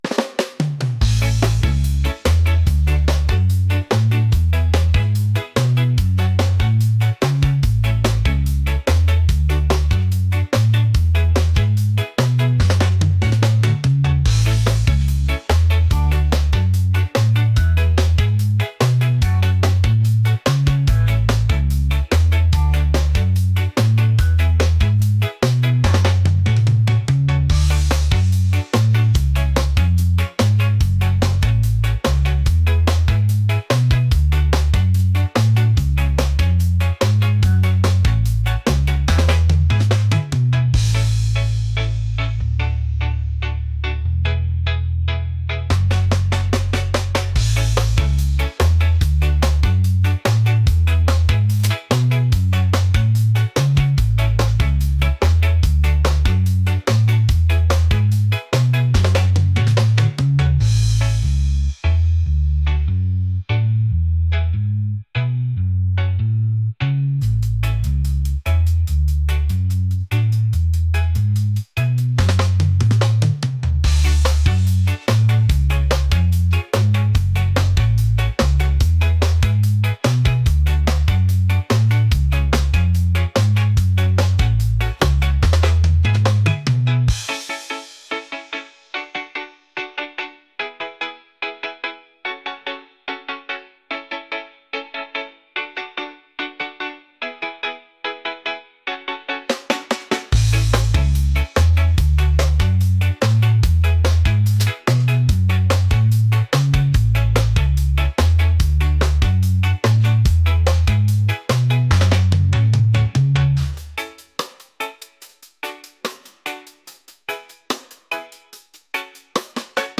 upbeat | catchy | reggae